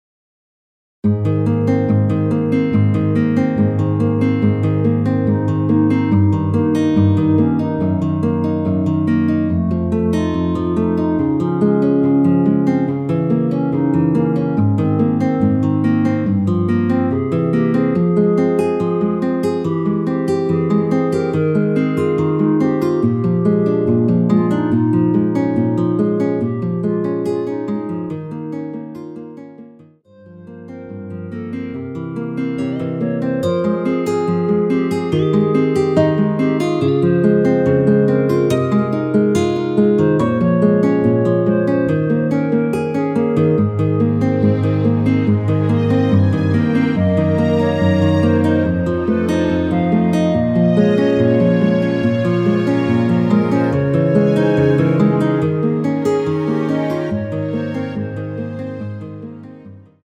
원키에서(-1)내린 멜로디 포함된 1절후 바로 후렴으로 진행 됩니다.(본문 가사및 미리듣기 확인)
Ab
앞부분30초, 뒷부분30초씩 편집해서 올려 드리고 있습니다.
중간에 음이 끈어지고 다시 나오는 이유는